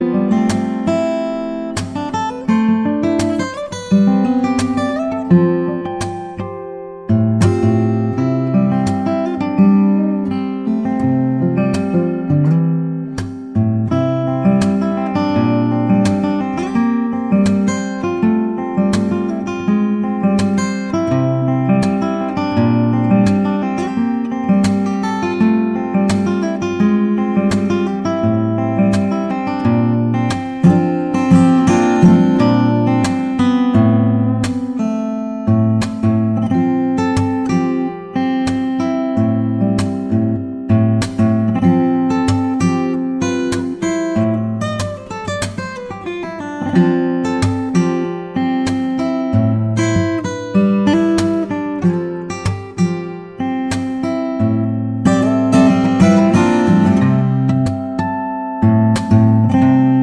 • Sachgebiet: Liedermacher